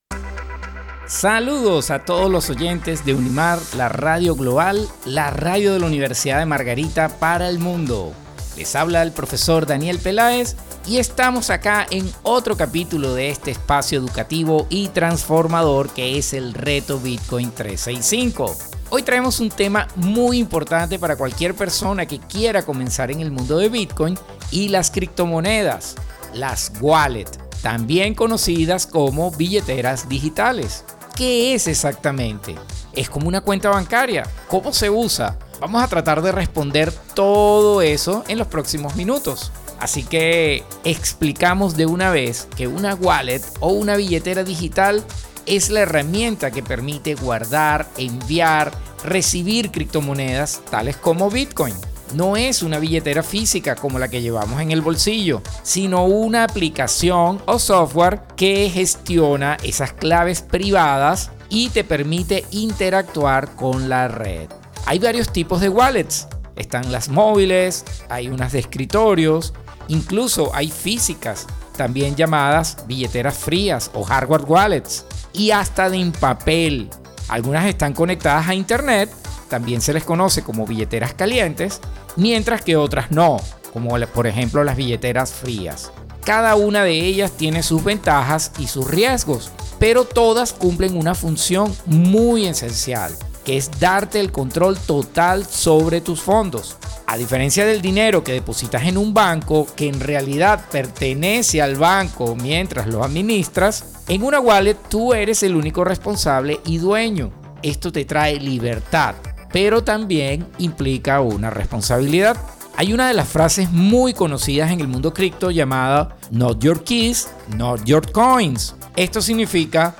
A través de entrevistas, testimonios y cápsulas informativas, los oyentes aprenderán estrategias prácticas para invertir de manera sostenida en Bitcoin utilizando el método de Dollar Cost Averaging (DCA).